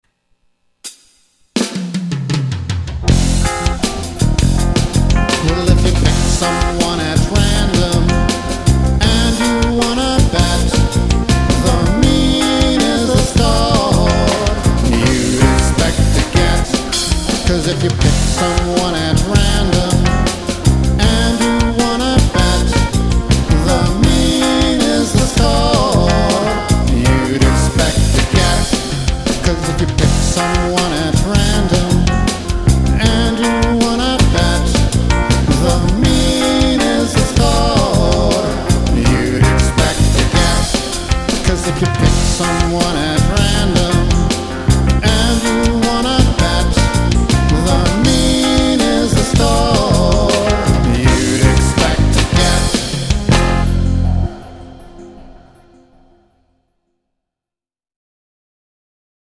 This is a page of stat music.